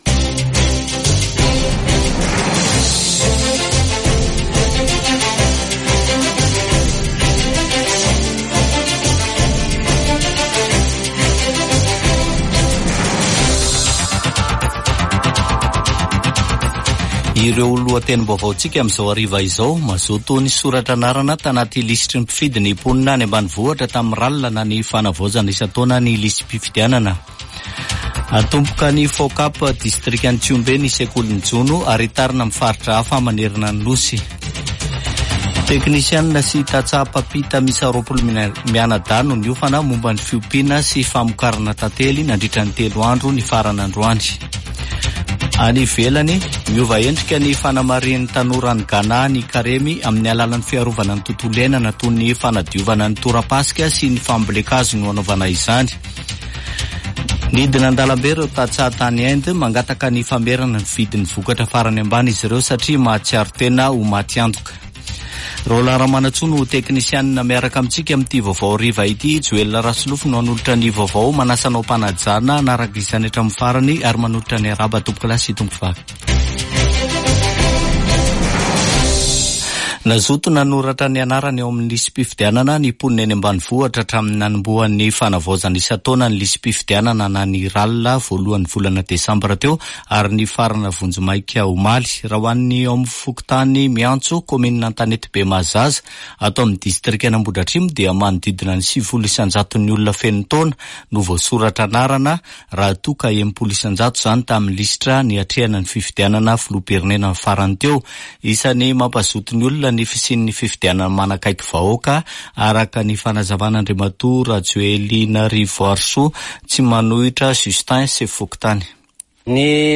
[Vaovao hariva] Zoma 16 febroary 2024